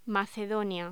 Locución: Macedonia
voz